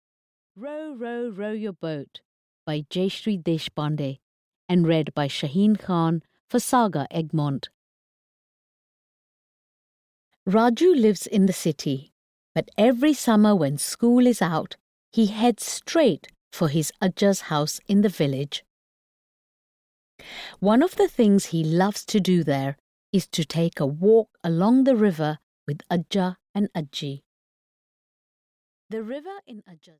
Row, Row, Row Your Boat (EN) audiokniha
Ukázka z knihy